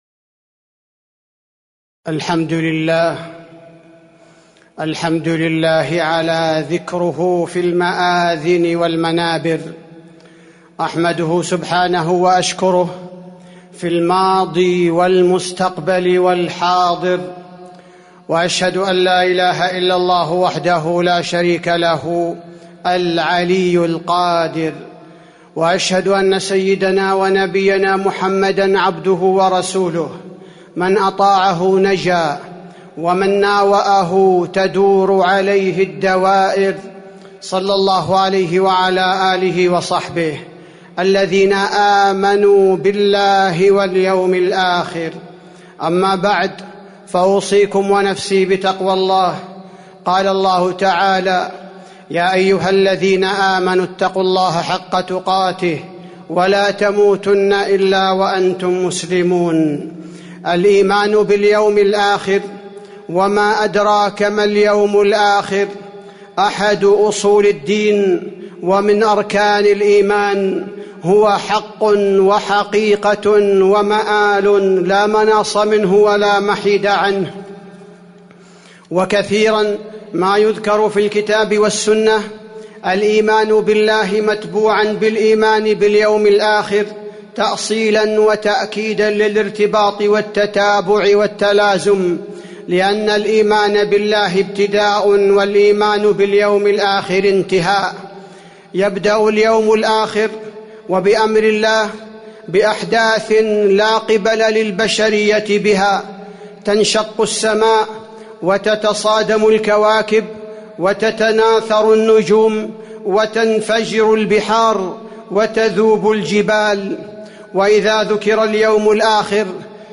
تاريخ النشر ١٧ صفر ١٤٤٣ هـ المكان: المسجد النبوي الشيخ: فضيلة الشيخ عبدالباري الثبيتي فضيلة الشيخ عبدالباري الثبيتي أثر الإيمان باليوم الآخر في حياة المسلم